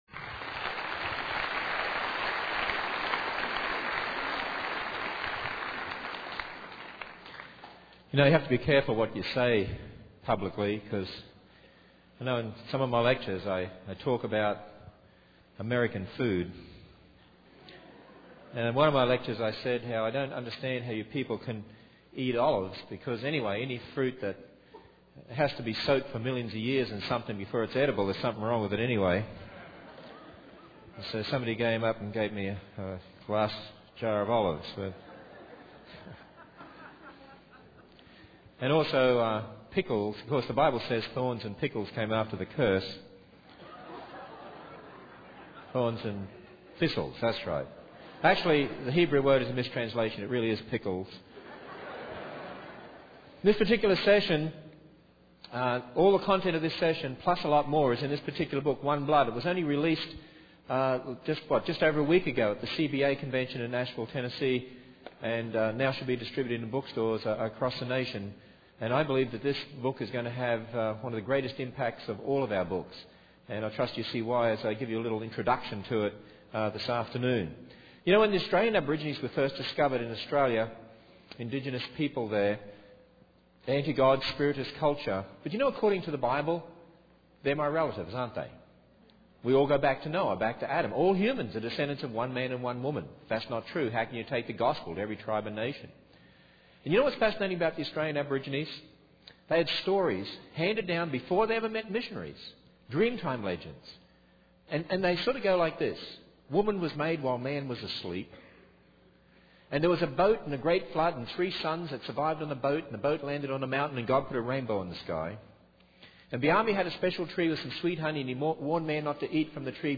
In this sermon, the speaker discusses the importance of recognizing that all humans are descendants of one man and one woman, going back to Noah and Adam. He challenges the secular world's division of the human population into racial groups and emphasizes that genetically, humans differ from each other by only 0.2%.